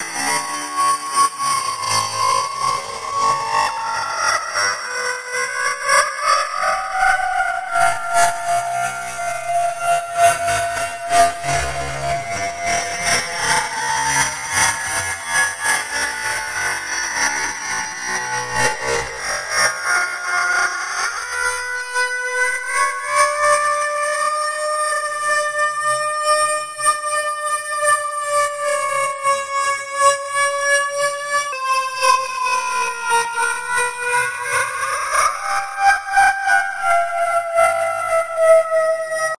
セクシーな感じに聞こえるノイズサウンドです。エロっぽいノイズ。女性の悲鳴にも似たノイズ。